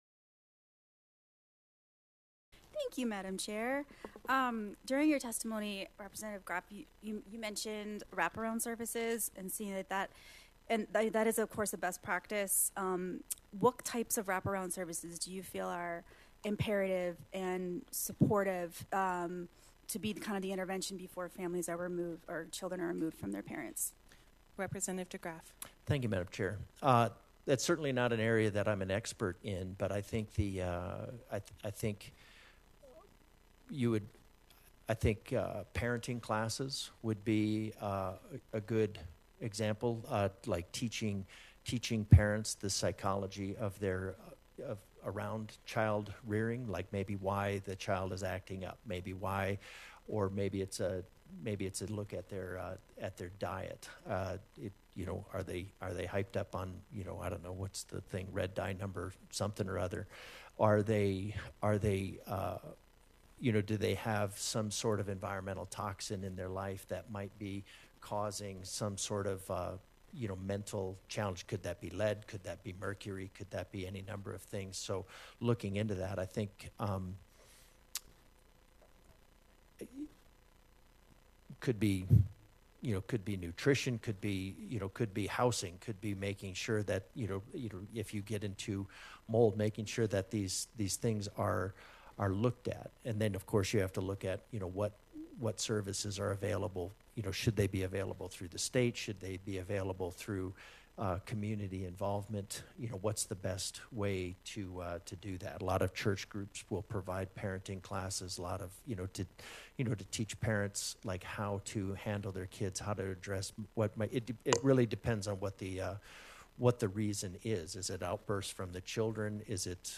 For example, one of the many problems with this particular bill, which Democrats noted, was how it could impact potential child abuse cases. DeGraaf often tossed out the term “wraparound services” to support children, but when Democratic Rep. Sue Ryden asked him to elaborate on that idea, DeGraaf was perplexed: